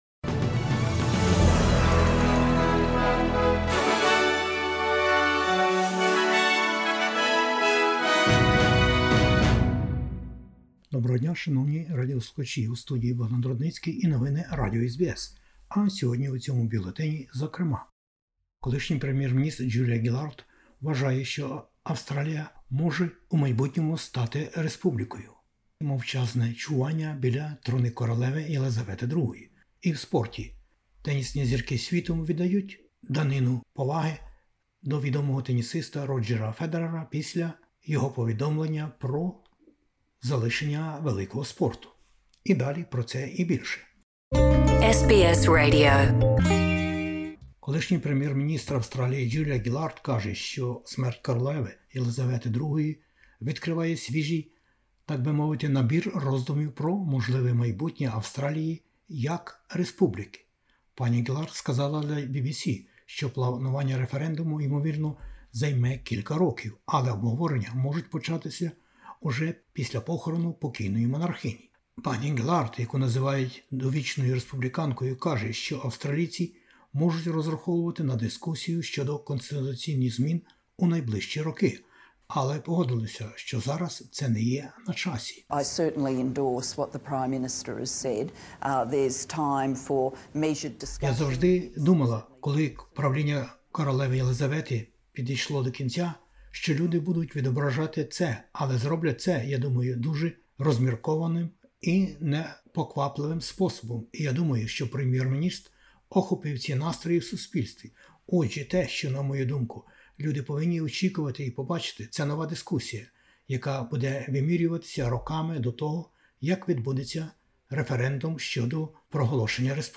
Бюлетень SBS новин - 16/09/2022